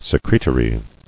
[se·cre·to·ry]